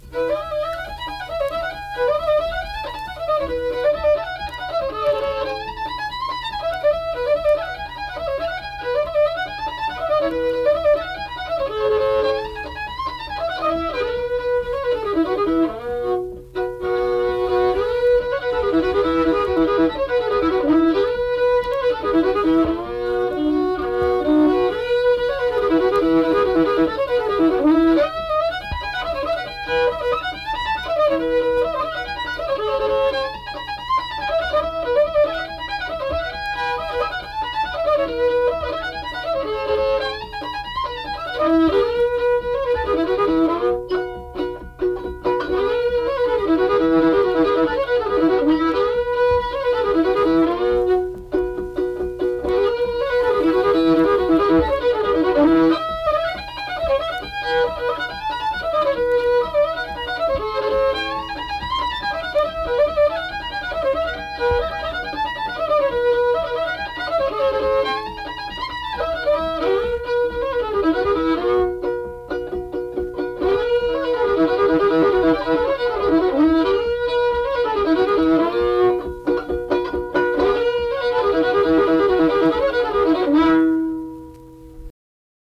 Hell Among The Yearlings - West Virginia Folk Music | WVU Libraries
Unaccompanied fiddle music and accompanied (guitar) vocal music performance
Instrumental Music
Fiddle